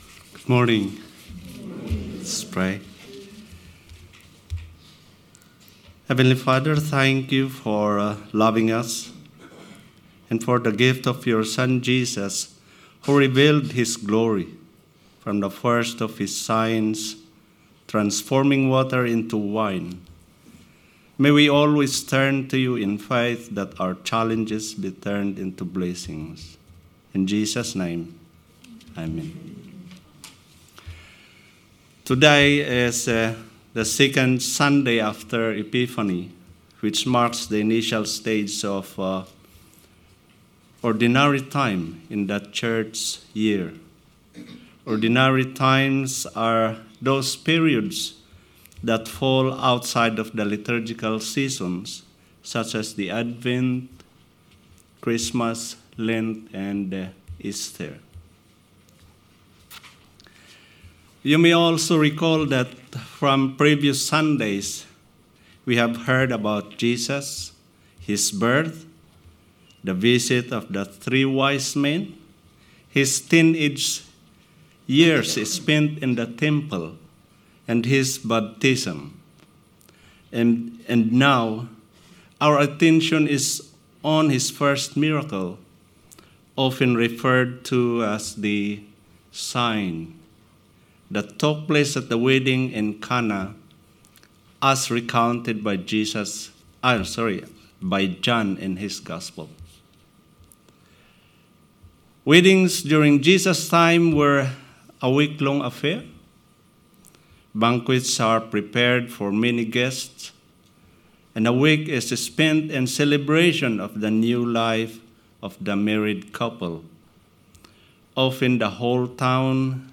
Sermon 19th January 2025 – A Lighthouse to the community